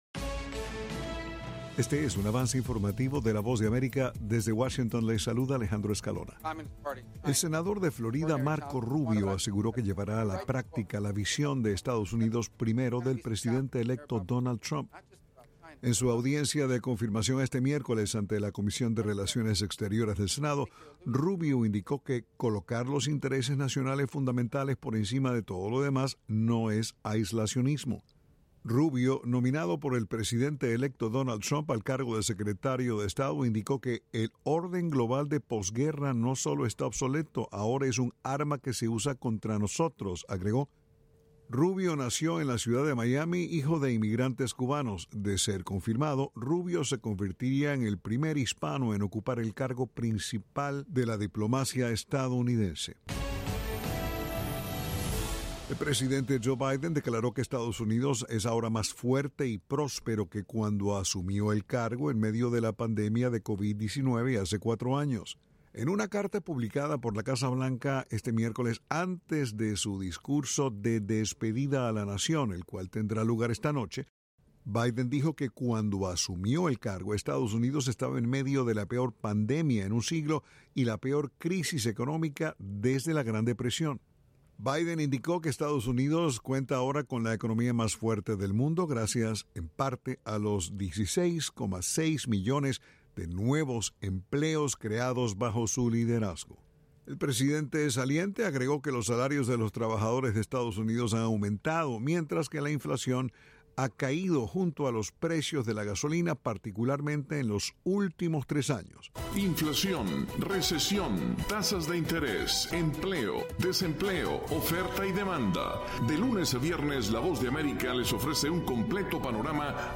El siguiente es un avance informativo de la Voz de América.
["Avance Informativo" es un segmento de noticias de la Voz de América para nuestras afiliadas en la región de América Latina y el Caribe].